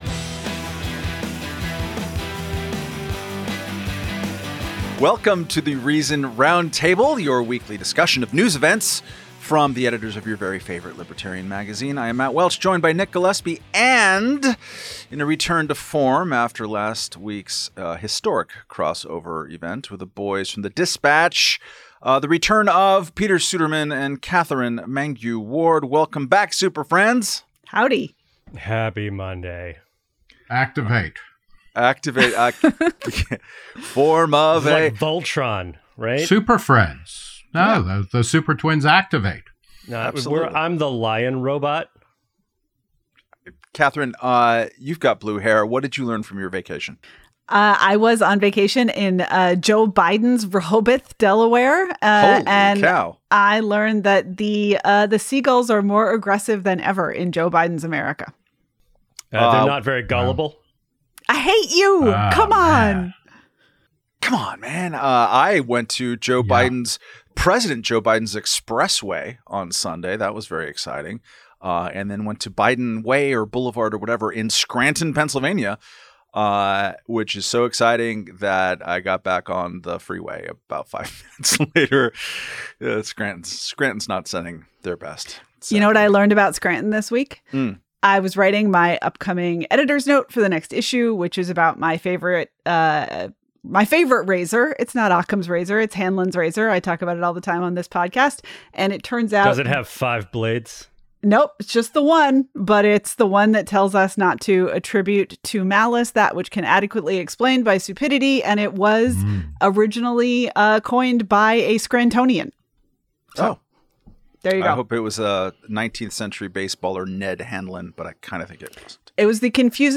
In this week's Reason Roundtable, we analyze Kamala Harris picking Gov. Tim Walz as her running mate, and consider the ongoing crisis in Venezuela.